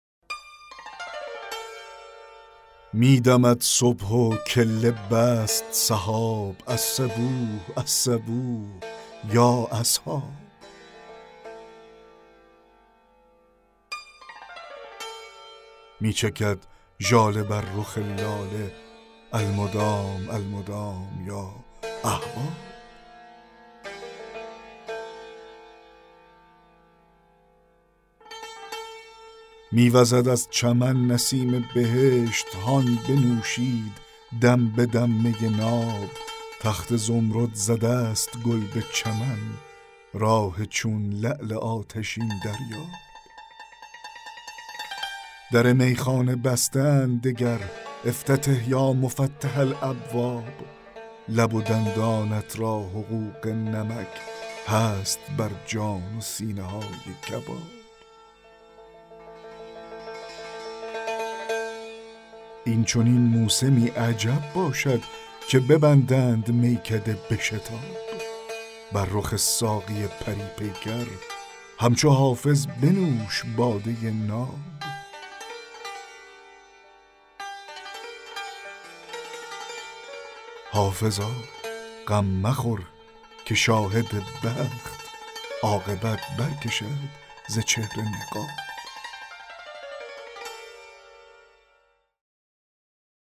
دکلمه غزل 13 حافظ
دکلمه-غزل-13-حافظ-صبح-می-دمد-و-کله-بست-سحاب.mp3